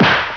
Effects
BaseballBatImpact.wav